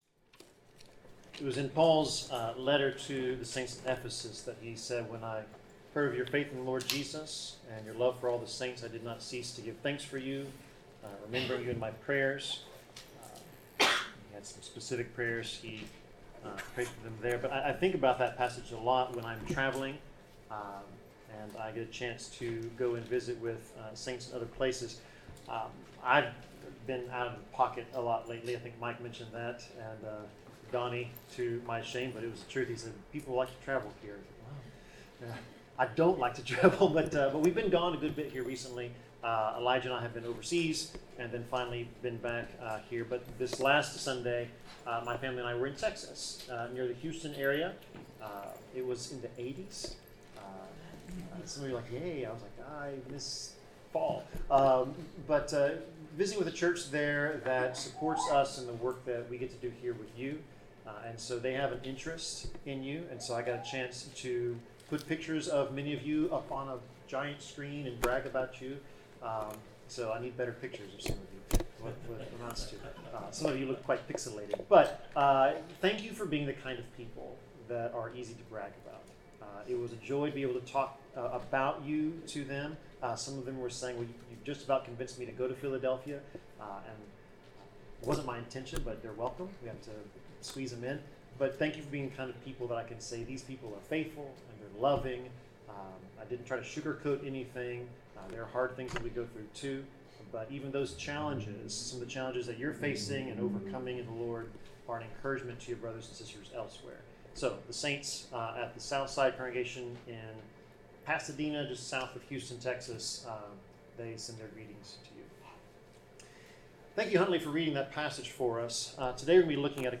Passage: Ecclesiastes 5:1-7 Service Type: Sermon